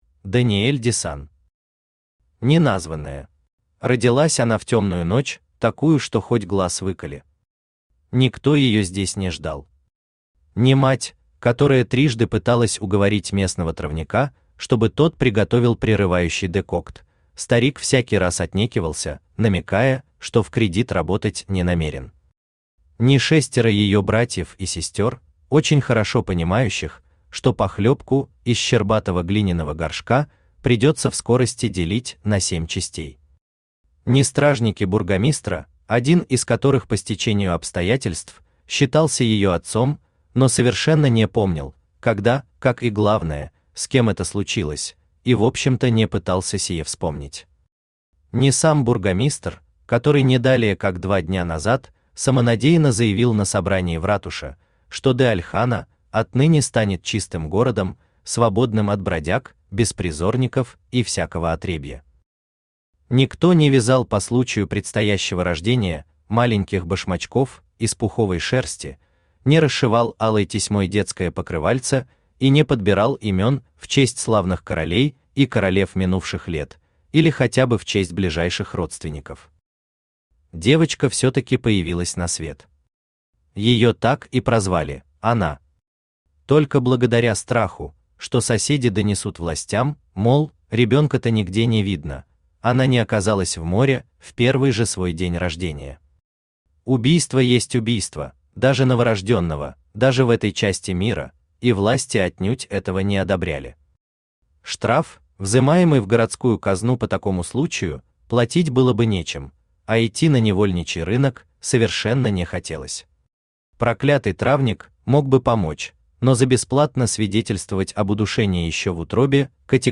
Aудиокнига Неназванная Автор Даниэль Дессан Читает аудиокнигу Авточтец ЛитРес.